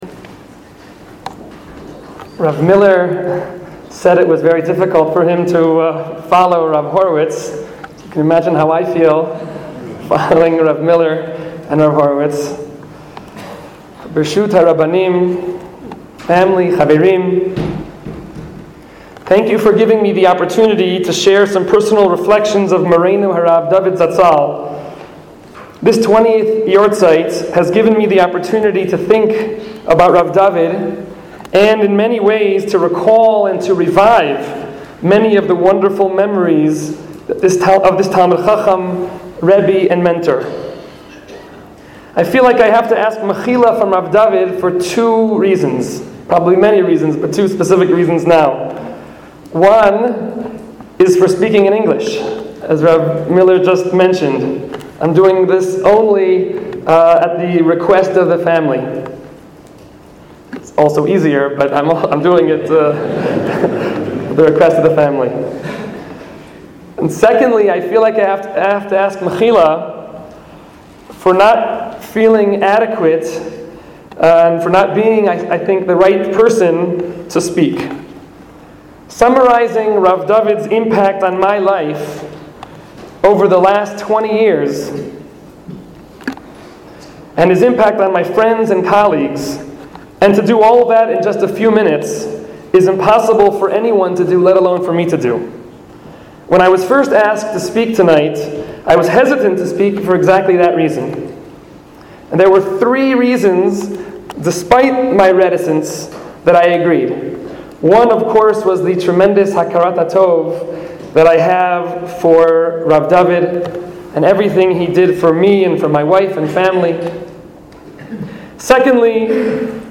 The talk took place on Wednesday, 4 Tammuz, 5773 (June 12, 2013) in Yeshiva University's Gruss Kolel in Jerusalem.